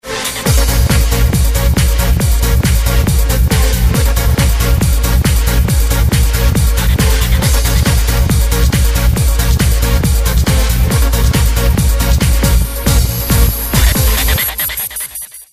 traffic.mp3